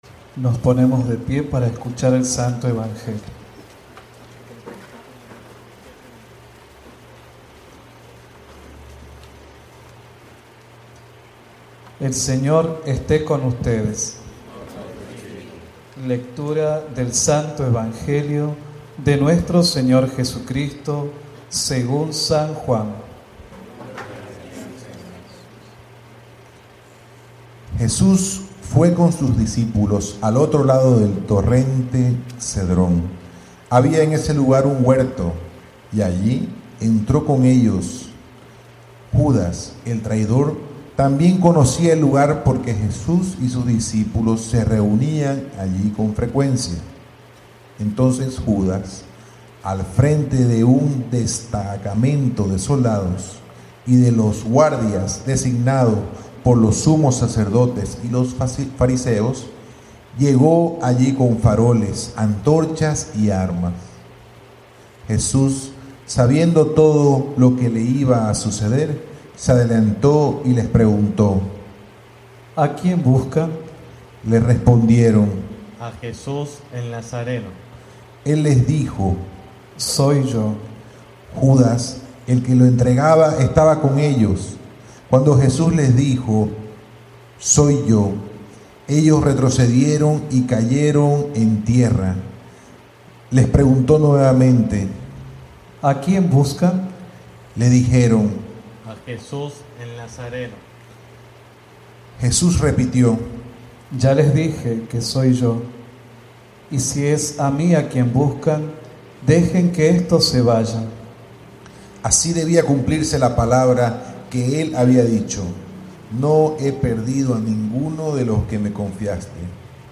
Homilía de Misa Viernes Santo